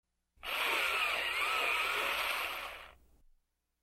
Бытовые звуки звуки скачать, слушать онлайн ✔в хорошем качестве